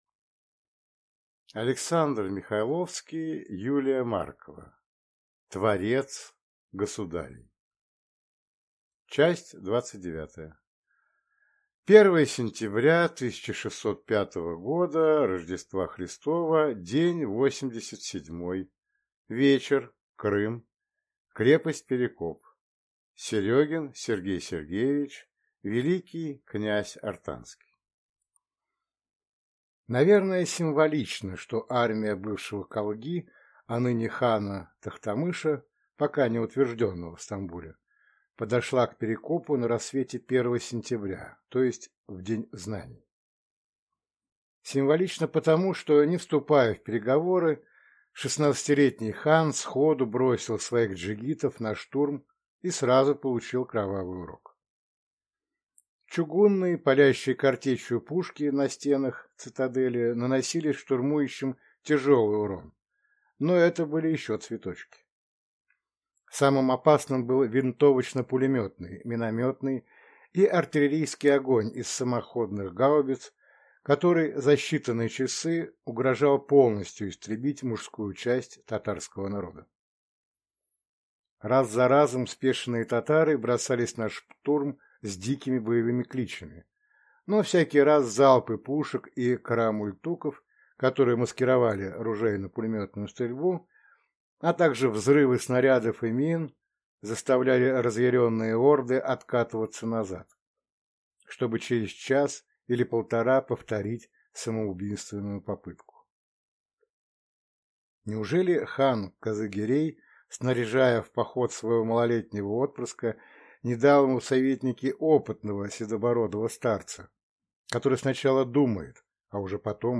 Аудиокнига Творец государей | Библиотека аудиокниг